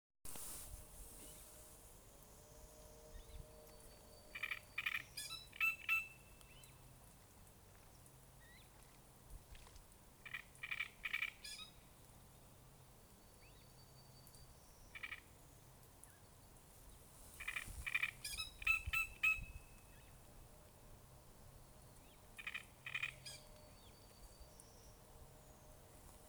дроздовидная камышевка, Acrocephalus arundinaceus
Administratīvā teritorijaRīga
СтатусПоёт